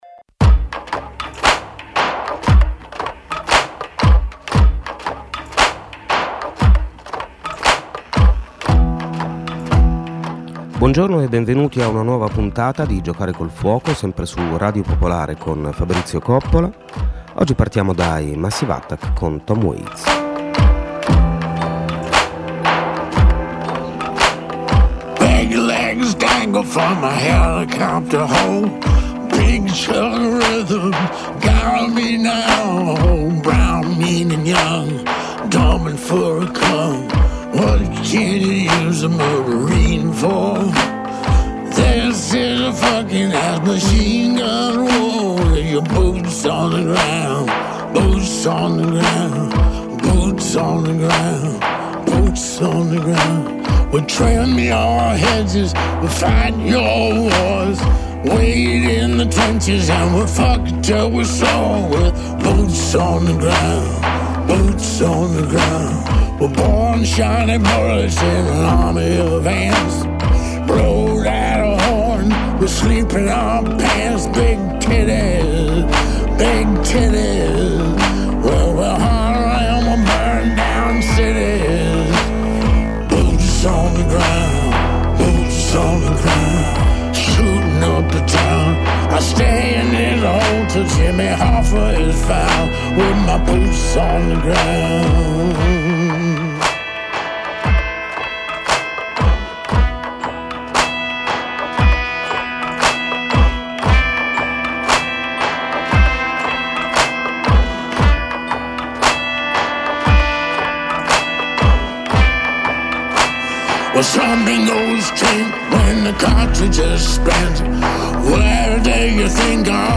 Ci muoveremo seguendo i percorsi segreti che legano le opere l’una all’altra, come a unire una serie di puntini immaginari su una mappa del tesoro. Memoir e saggi, fiction e non fiction, poesia (moltissima poesia), musica classica, folk, pop e r’n’r, mescolati insieme per provare a rimettere a fuoco la centralità dell’esperienza umana e del racconto che siamo in grado di farne.